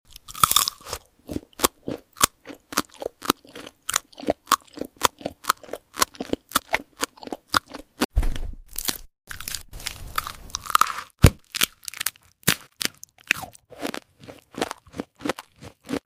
Tarantula Up Close ASMR 🕷🔥 She bites into a crispy fried tarantula—leg first. Filmed from two angles, this ASMR short is all about the hairy crunch, the daring flavor, and the intense sound of fangs meeting food.